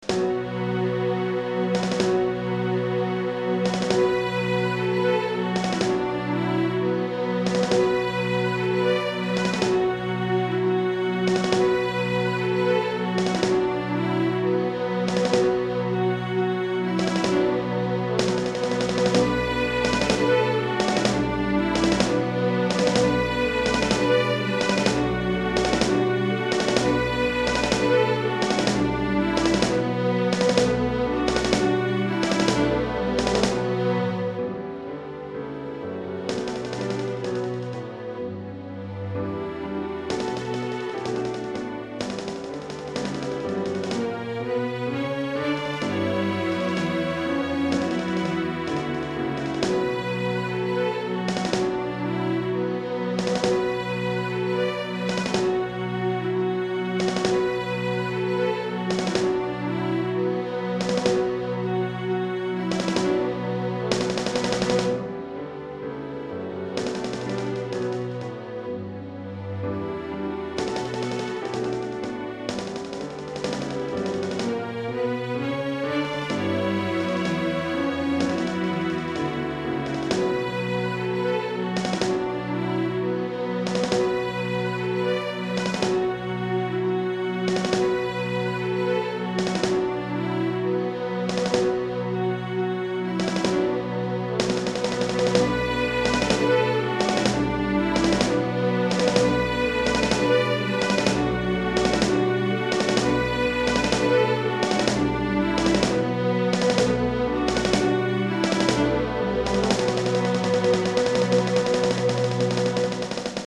Répertoire pour Musique de chambre